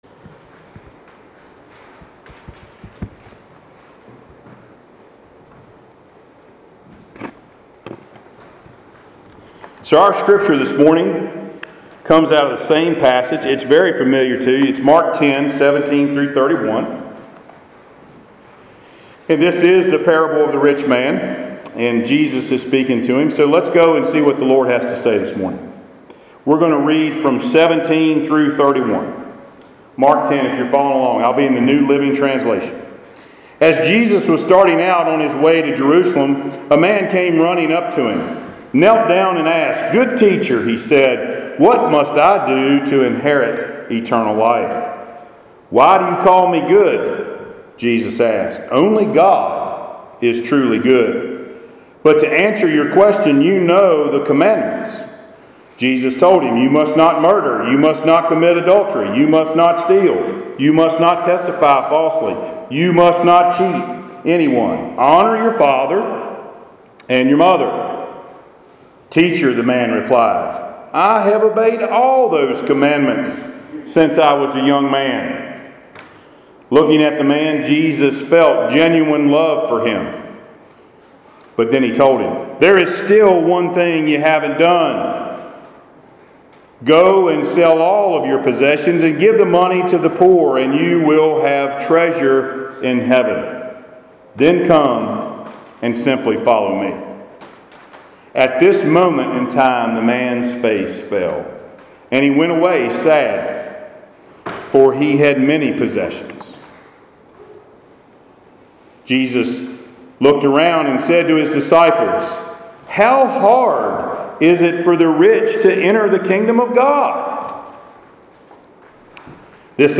Passage: Mark 10: 17-31 Service Type: Sunday Worship